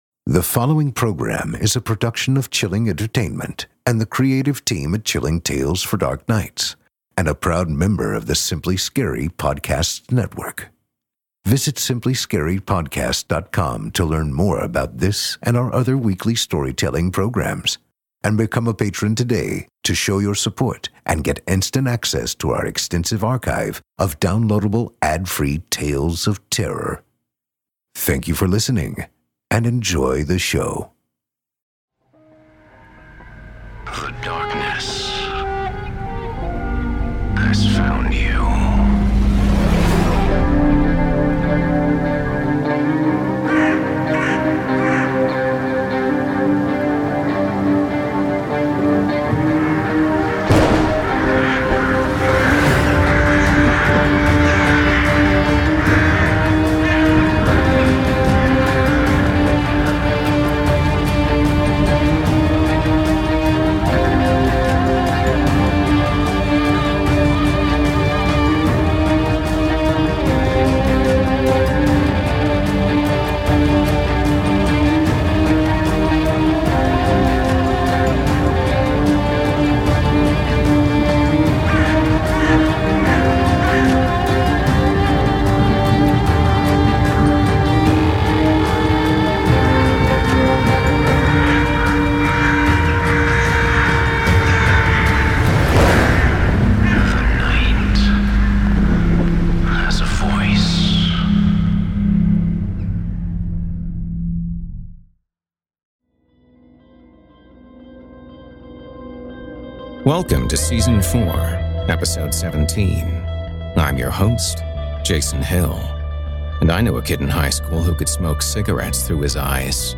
This week's episode features an audio adaptation of Geoff Sturtevant's 'Anything for my Bubela" Part One.